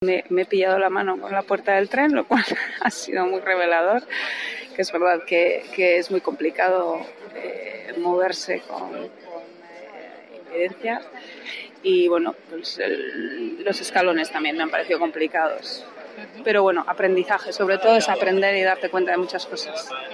En la experiencia con los políticos y directivos del transporte público, la directora de Transportes de Gipuzkoa, Eluska Renedo, confesaba haber sentido algo más que inquietud